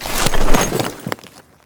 main Divergent / mods / Ledge Climbing Sounds Redone / gamedata / sounds / ledge_grabbing / Vaulting / Light / light2.ogg 24 KiB (Stored with Git LFS) Raw Permalink History